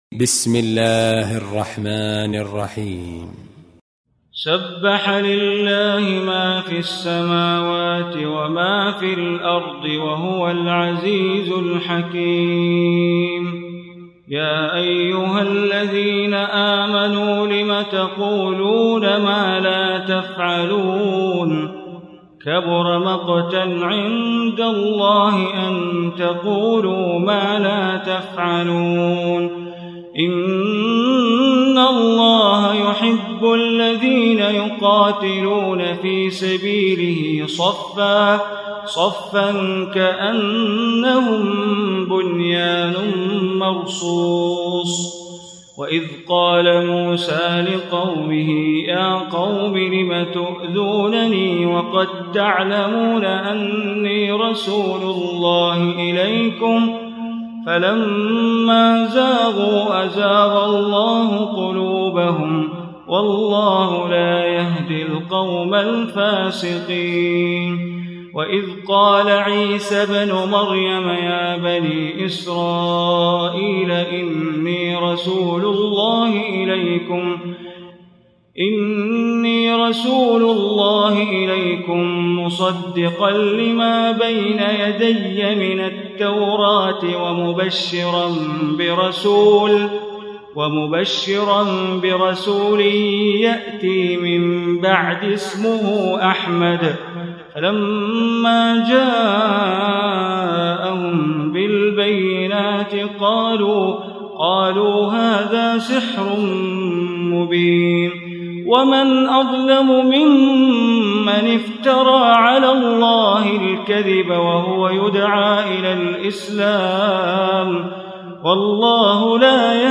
Surah Saff Recitation by Sheikh Bandar Baleela
Surah Saff, listen online mp3 tilawat / recitation in Arabic recited by Imam e Kaaba Sheikh Bandar Baleela.